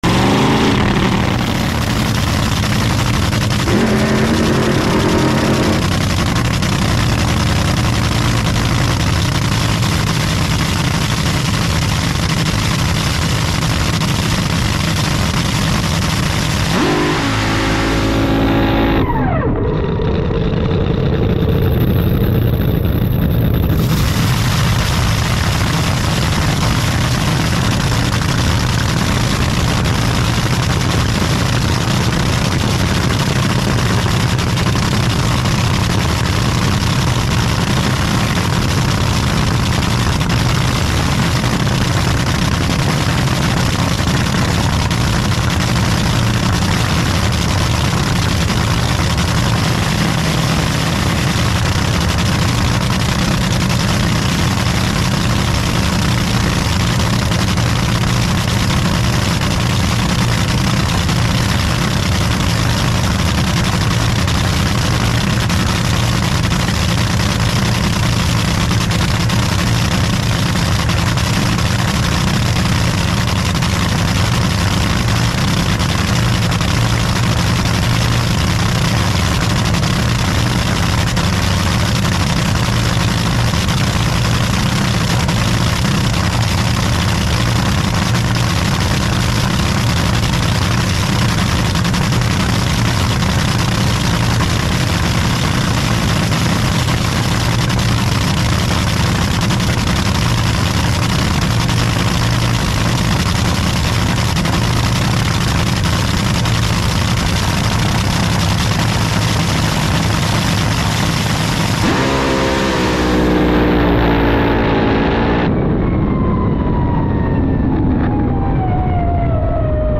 POV - You’re a gopro attached to a top fuel wing